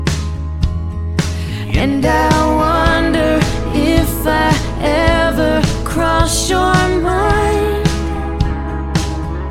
辅音/s/与/j/连读时, 被同化为/∫/